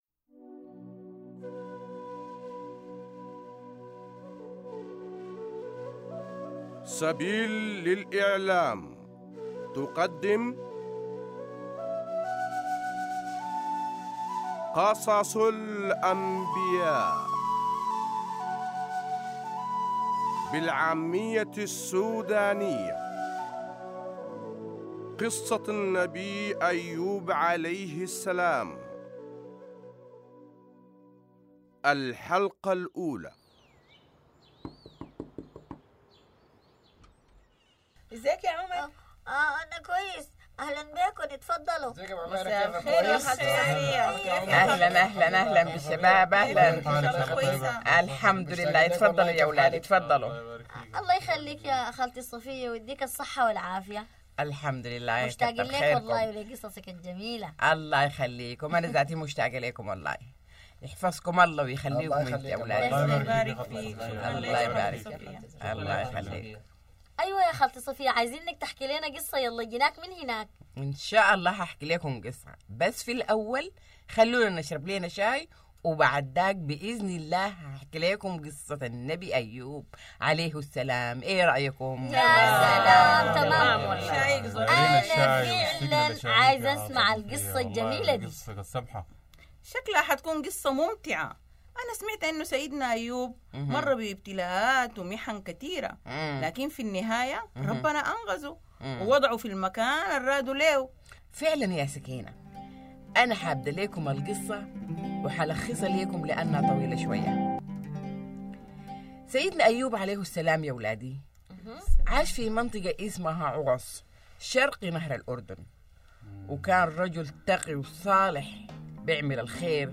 Job | Sudanese Arabic